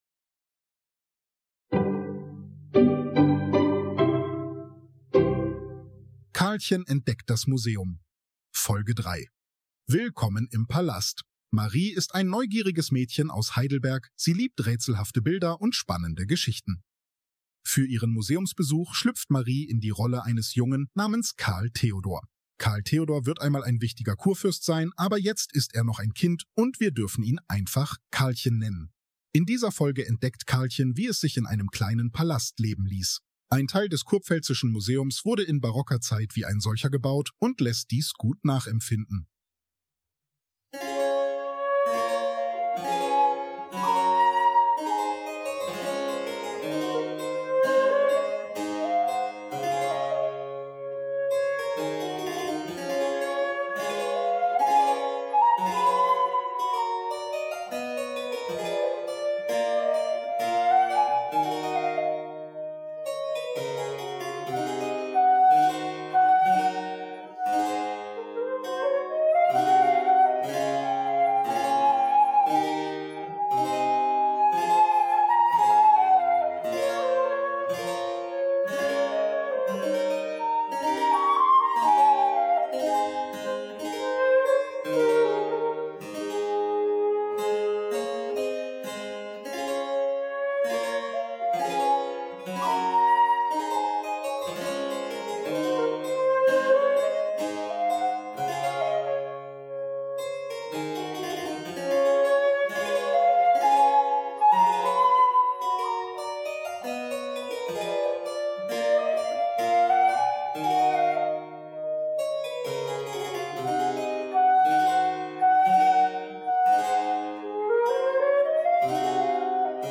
Und wie klingt ein echtes Cembalo? Eine spannende Zeitreise durch das barocke Leben – mit Musik, Geschichten und vielen kleinen Überraschungen.